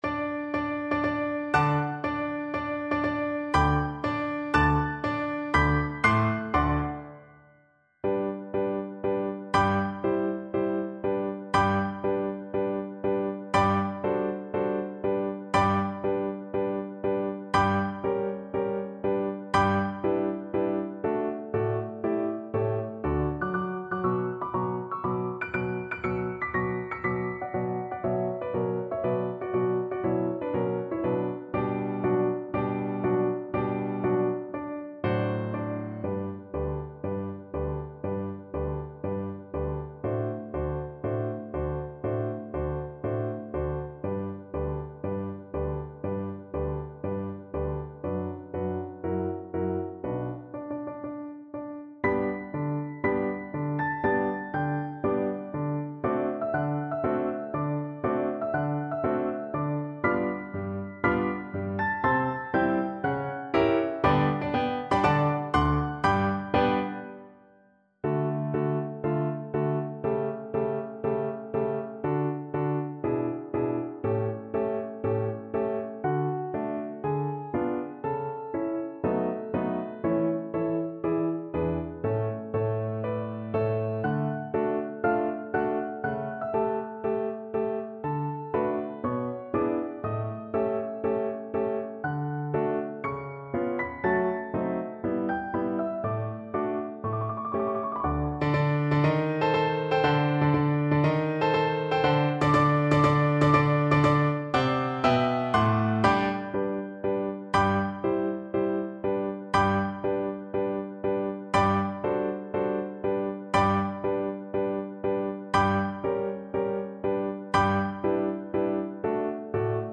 Cello version
Allegro marziale (View more music marked Allegro)
4/4 (View more 4/4 Music)
Classical (View more Classical Cello Music)